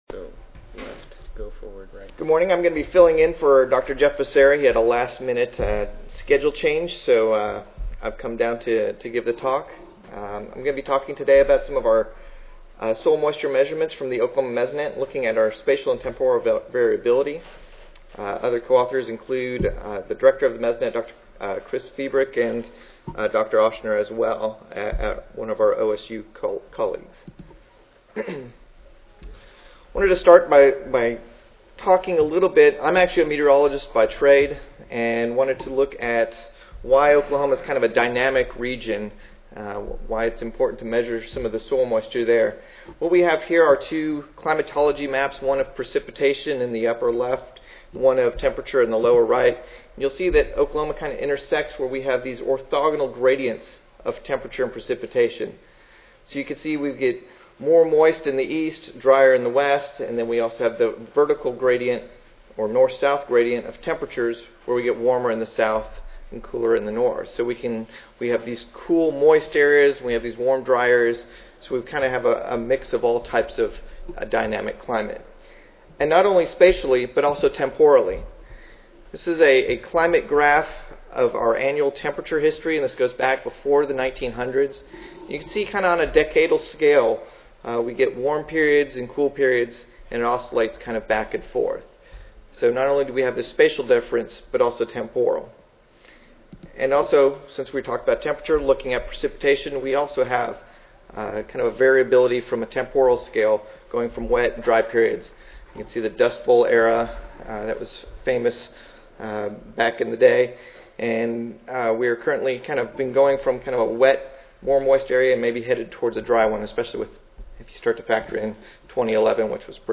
Oklahoma State University Recorded Presentation Audio File